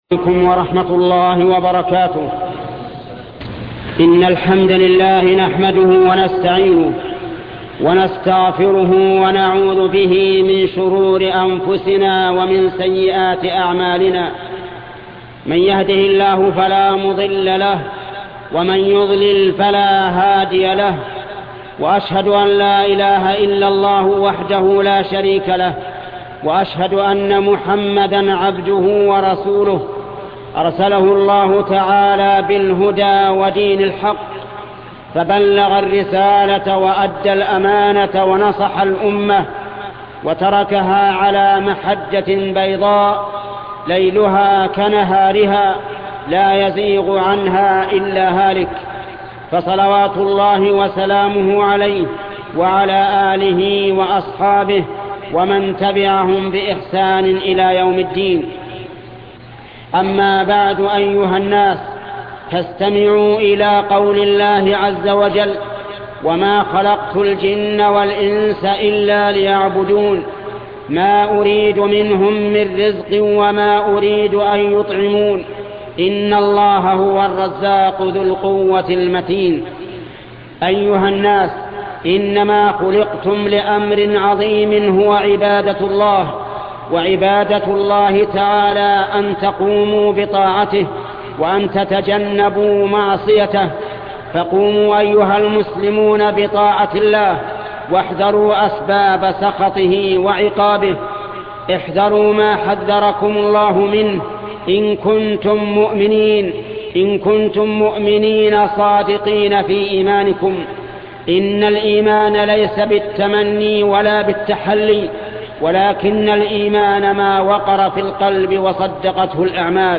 خطبة قولنا في الأسهم الشيخ محمد بن صالح العثيمين